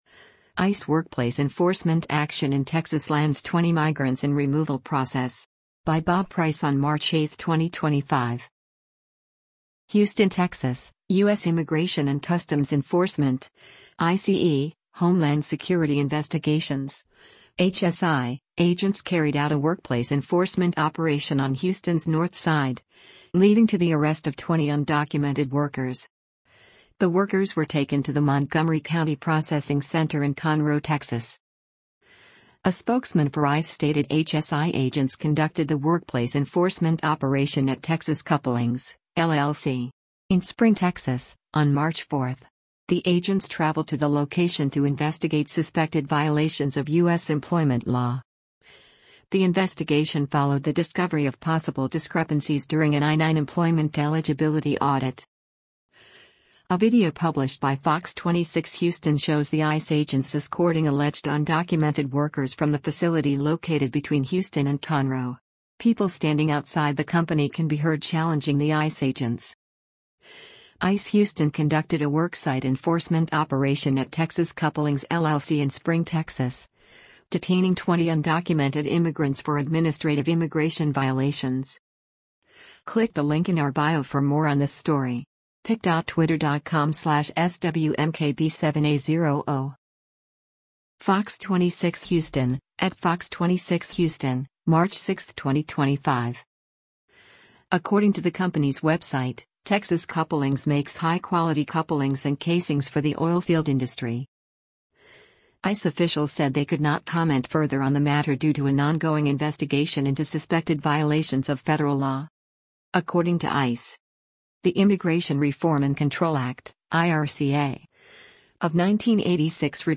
A video published by Fox 26 Houston shows the ICE agents escorting alleged undocumented workers from the facility located between Houston and Conroe. People standing outside the company can be heard challenging the ICE agents.